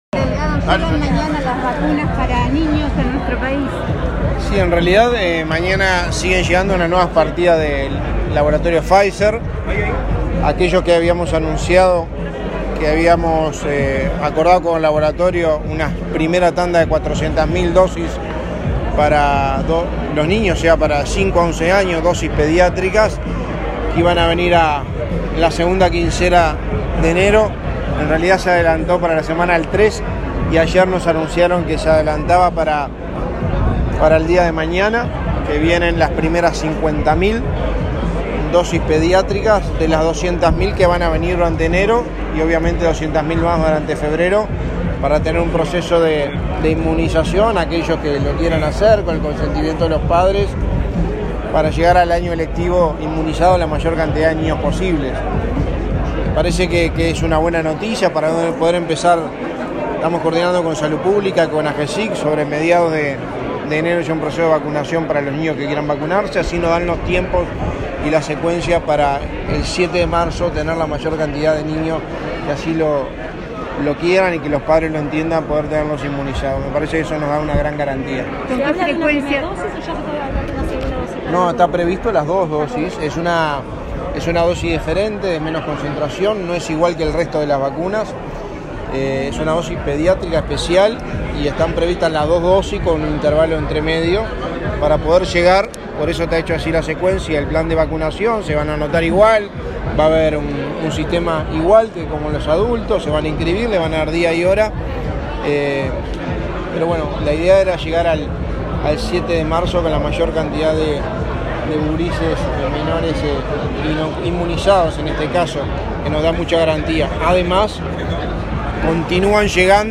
Declaraciones a la prensa del secretario de Presidencia, Álvaro Delgado